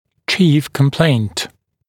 [ʧiːf kəm’pleɪnt][чи:ф кэм’плэйнт]основная жалоба